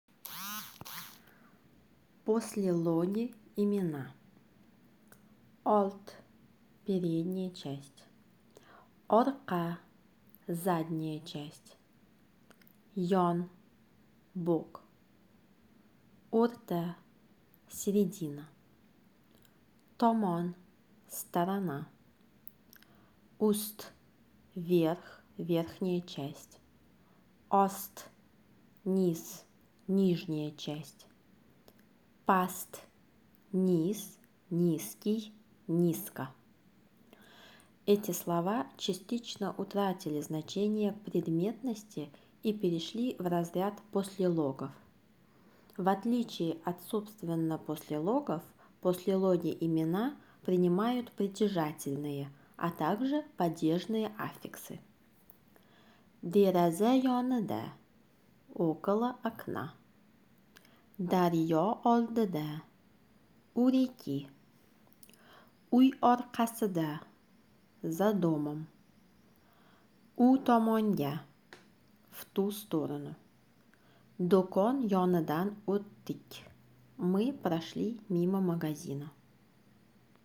Узбекский язык - аудиоуроки